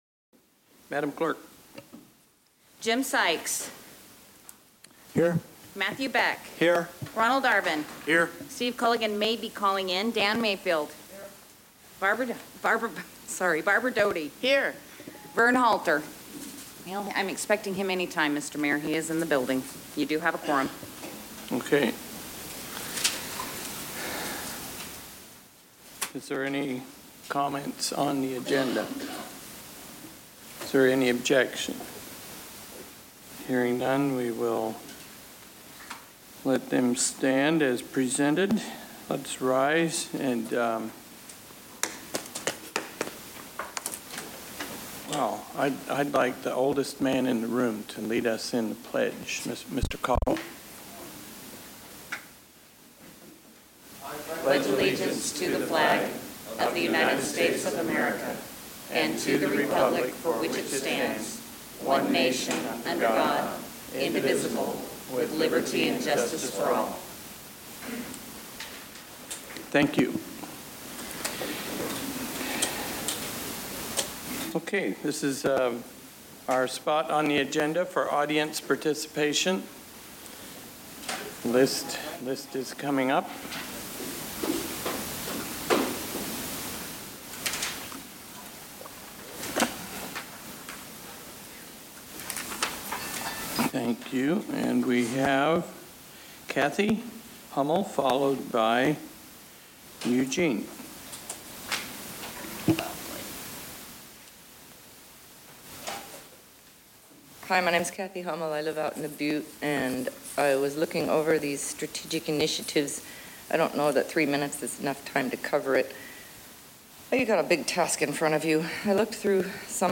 MatSu Borough Assembly Special and Regular Meetings 9.1.2015
Sep 2, 2015 | Borough Assembly Meetings